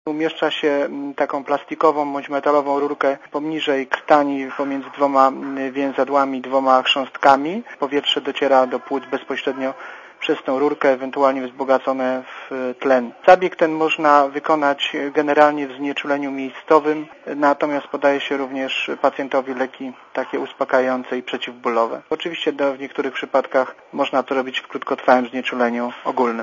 Mówi lekarz ze szpitala MSWiA w Warszawie